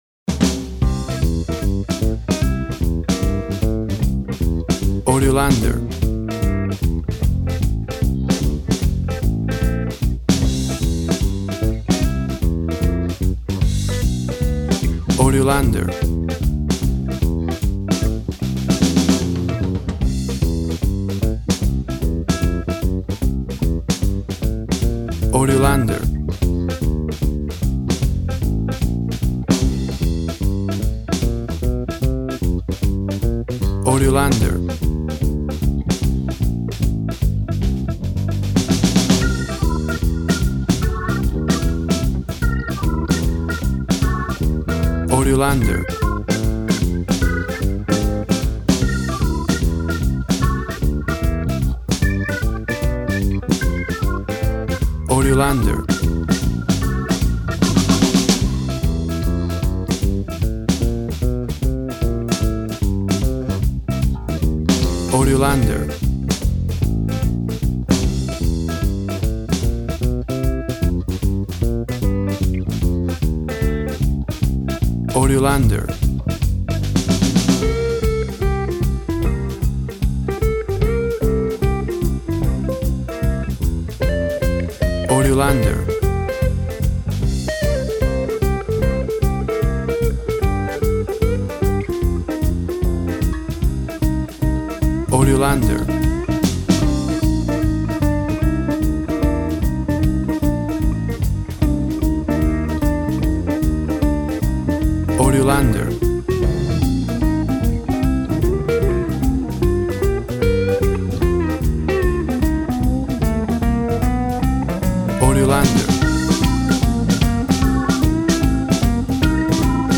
Tempo (BPM) 152